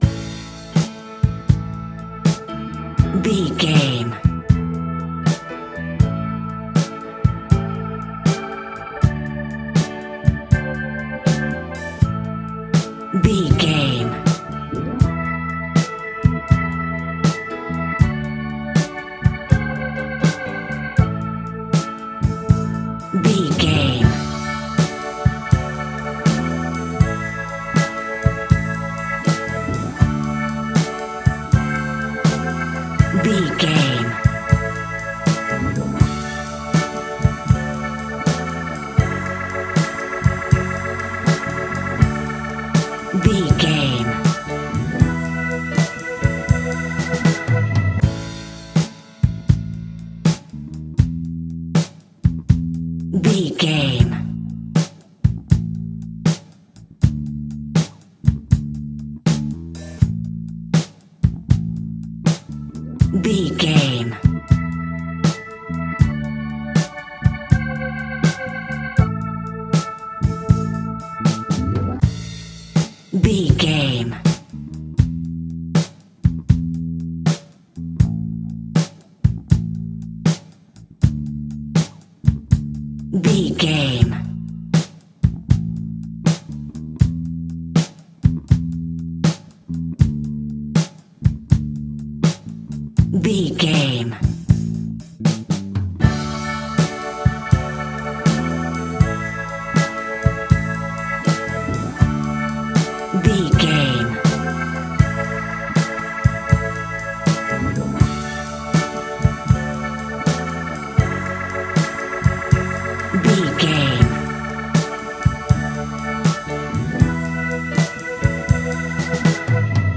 Ionian/Major
funky
uplifting
bass guitar
electric guitar
organ
drums
saxophone
groovy